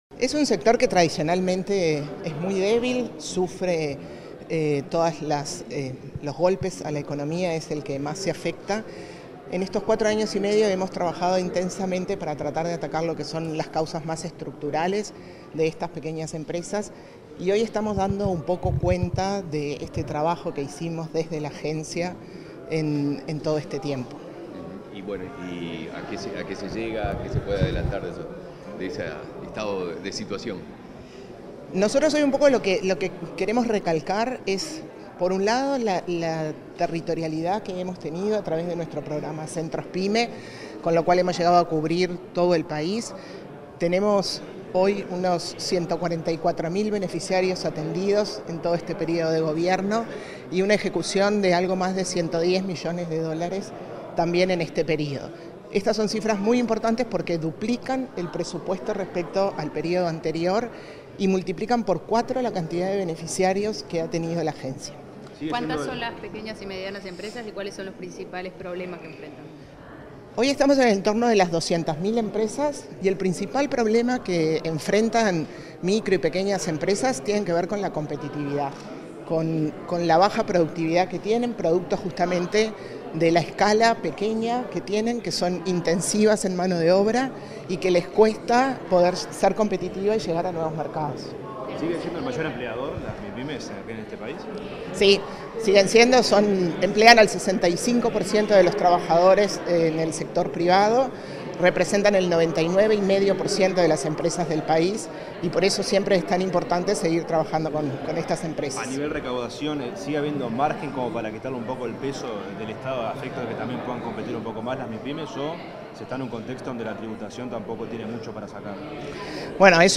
Declaraciones de la presidenta de la ANDE, Carmen Sánchez
Declaraciones de la presidenta de la ANDE, Carmen Sánchez 13/08/2024 Compartir Facebook X Copiar enlace WhatsApp LinkedIn Tras conmemorar el Día Nacional de las Mipymes, este 13 de agosto, la presidenta de la Agencia Nacional de Desarrollo (ANDE), Carmen Sánchez, fue entrevistada para medios informativos.